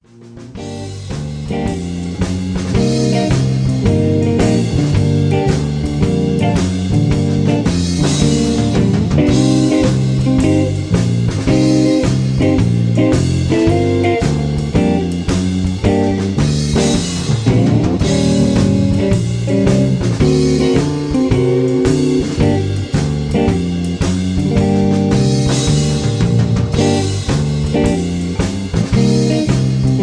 Basic 12 bar Minor blues Gm 110.
These are real Tracks not midi files.